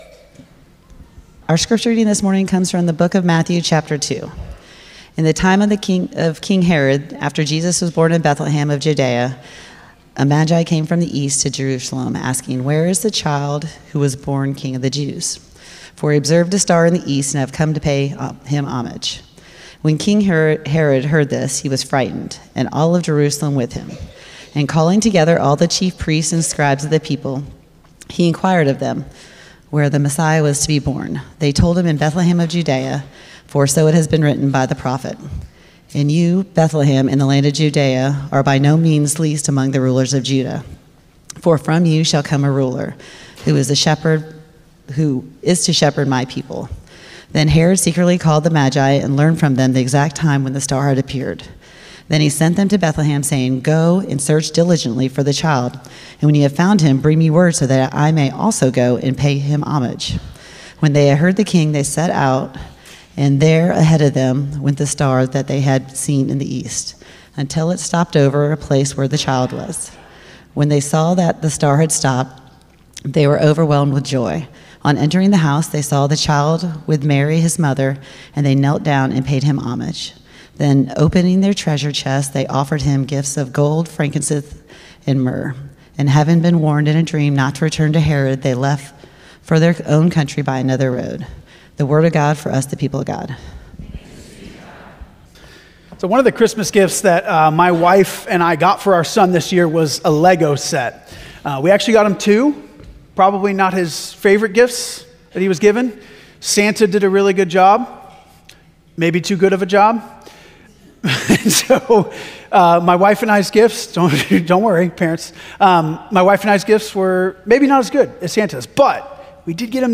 First Cary UMC's First on Chatham Sermon &ndash